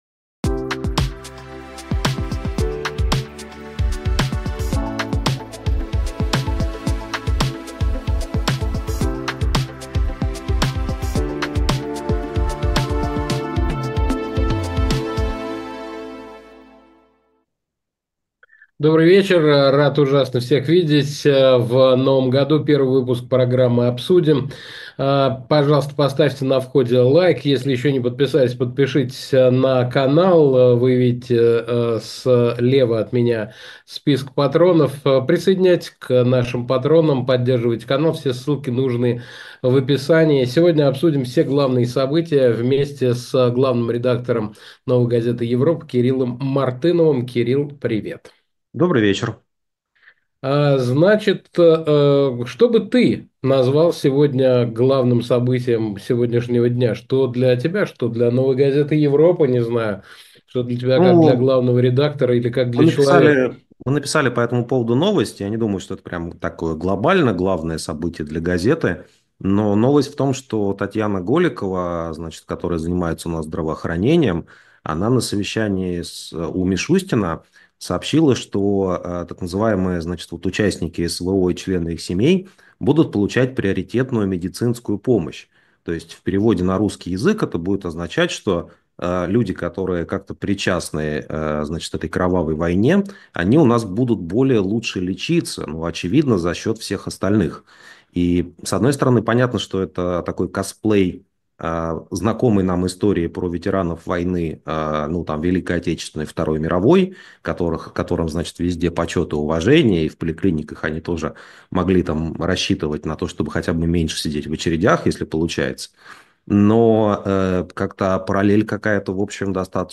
Программу ведет Александр Плющев.